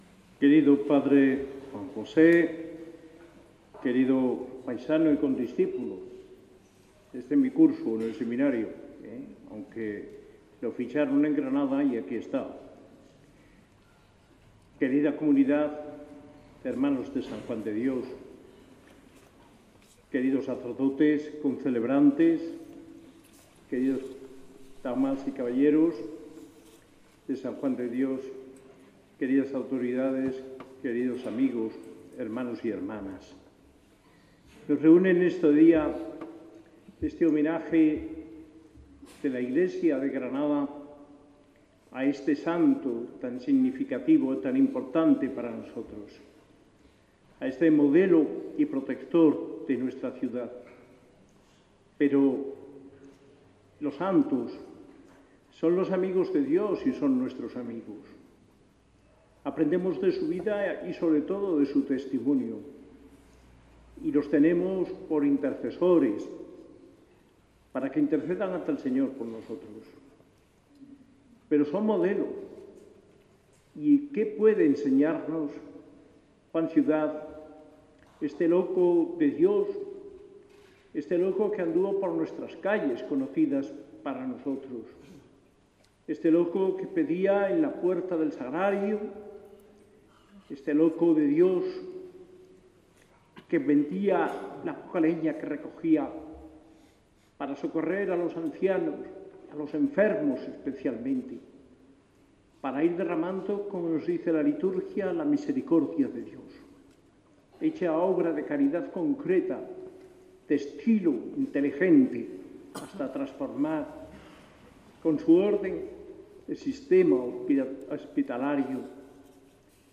Con la Eucaristía presidida por nuestro arzobispo en la Basílica, en cuya homilía habló del “legado” de amor que el copatrono de la ciudad deja entre nosotros.